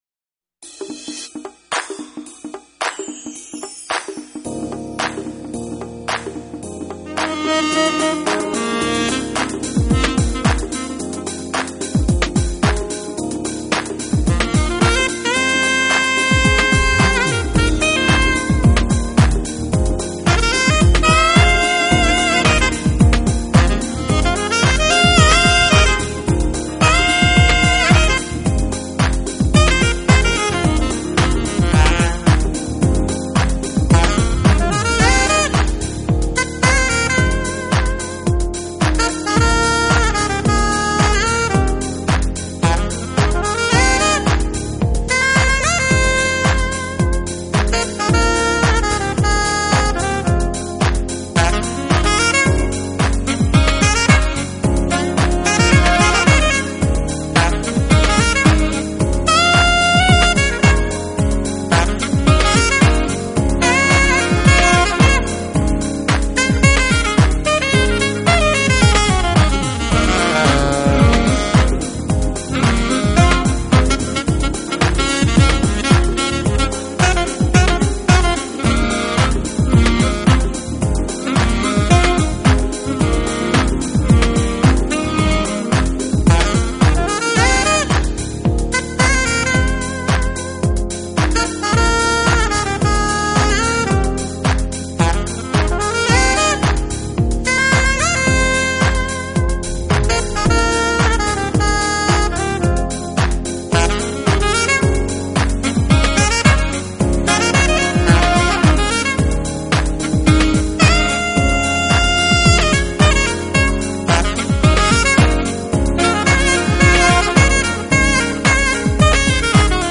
专辑风格：Smooth Jazz